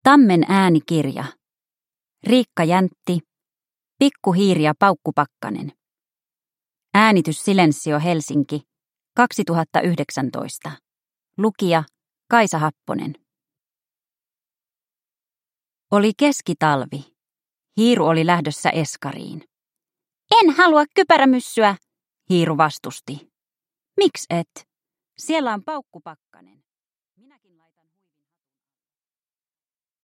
Pikku hiiri ja paukkupakkanen – Ljudbok – Laddas ner